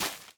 Minecraft Version Minecraft Version 1.21.5 Latest Release | Latest Snapshot 1.21.5 / assets / minecraft / sounds / block / big_dripleaf / step1.ogg Compare With Compare With Latest Release | Latest Snapshot
step1.ogg